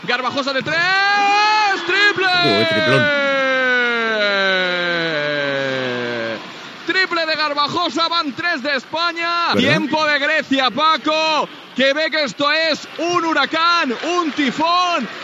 Narració d'un llançament triple d'Espanya i temps mort de Grècia.
Esportiu